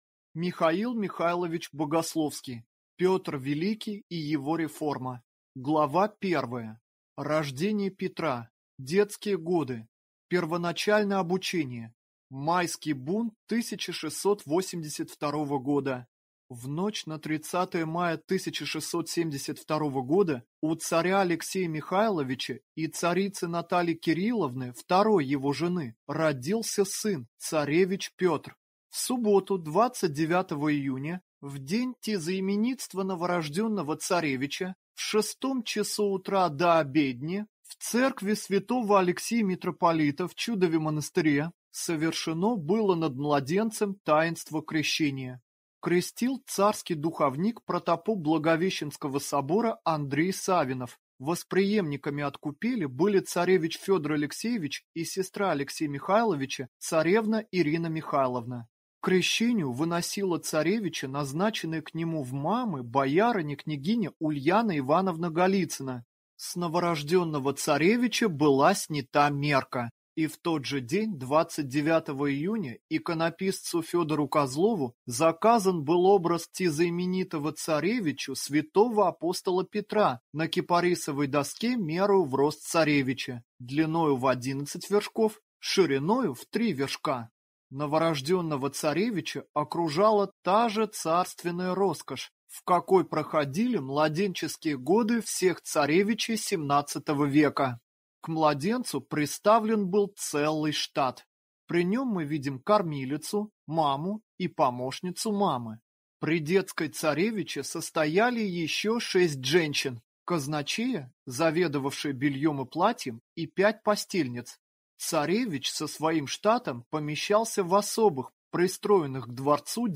Аудиокнига Петр Великий и его реформа | Библиотека аудиокниг